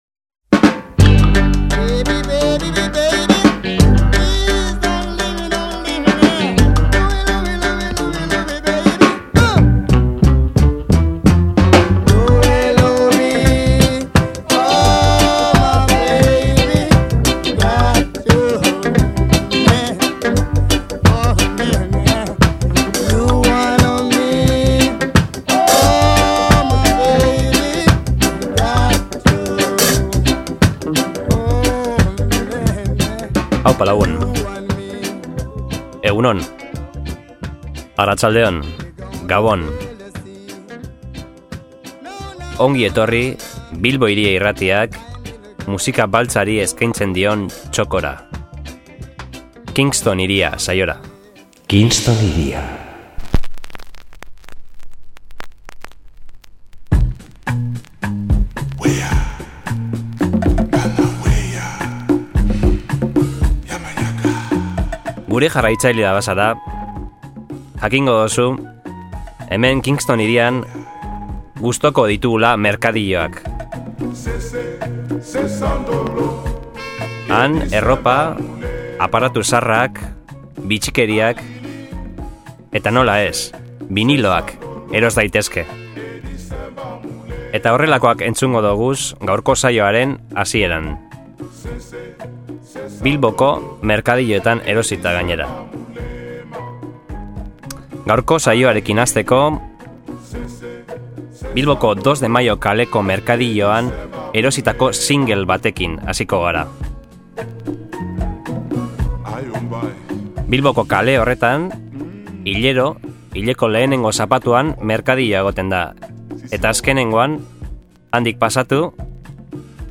Musika afrikarra, Hip-Hop, Soul, Dancehall, Reggae, Ska…